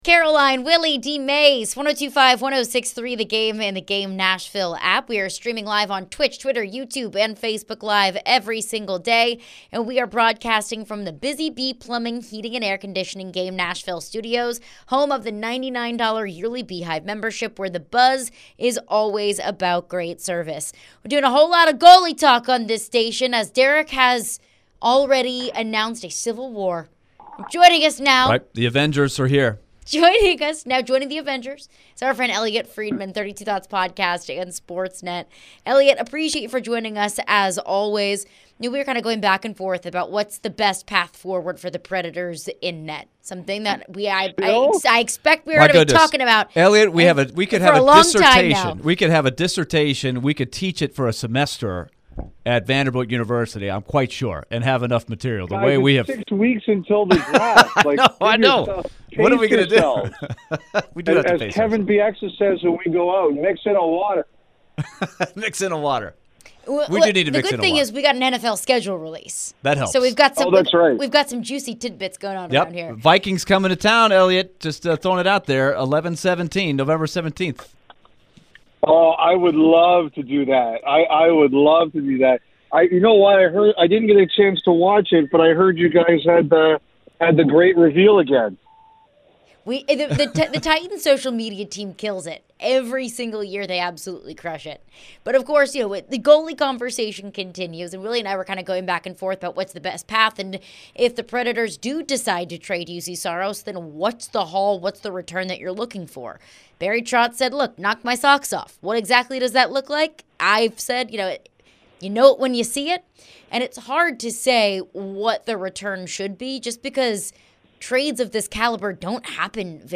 the guys talk with Elliotte Friedman at Sportsnet Hockey Night in Canada. Elliotte discussed the Preds and their goalie situation. Who could the Preds trade Saros for? What team would be interested in Juuse Saros?